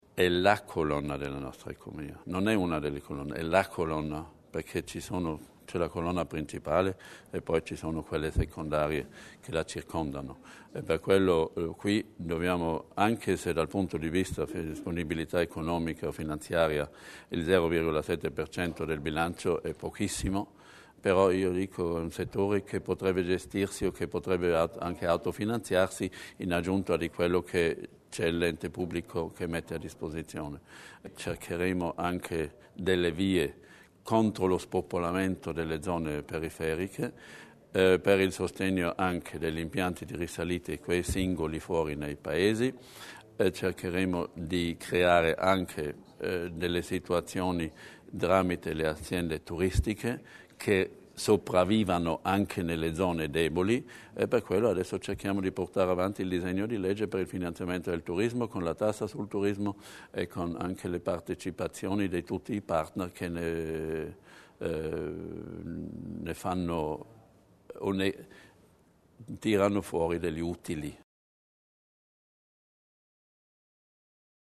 Marketing professionale, cooperazione tra organizzazioni turistiche e aree vicine, nuove forme di finanziamento: lungo queste piste si svilupperà l'attività del turismo in Alto Adige nel 2012: lo ha sottolineato l'assessore provinciale Hans Berger presentando i punti chiave del settore nel 2012, che nella bozza di bilancio può contare su 36,5 milioni di euro.